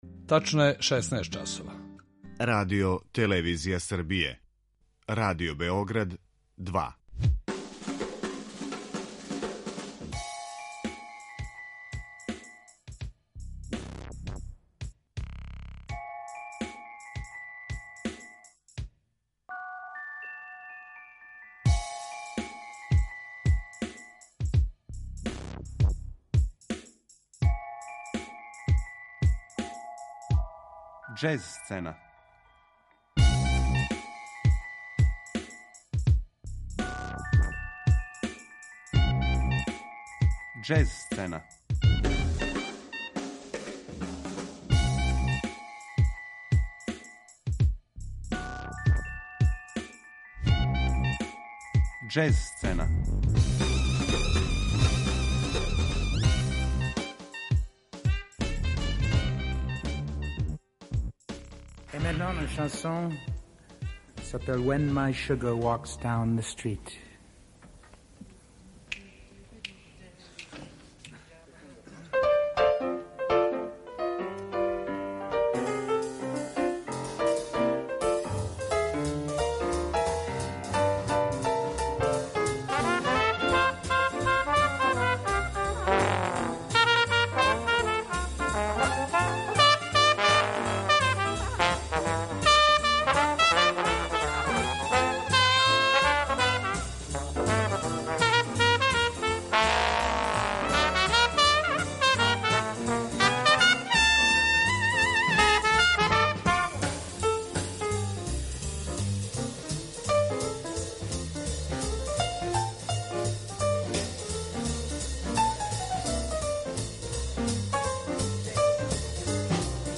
Слушаћемо избор музике са њихових досадашњих албума.